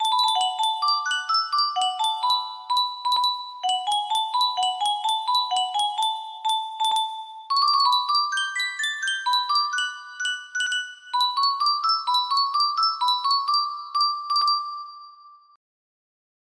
Yunsheng Music Box - Wedding Day at Troldhaugen 4826 music box melody
Full range 60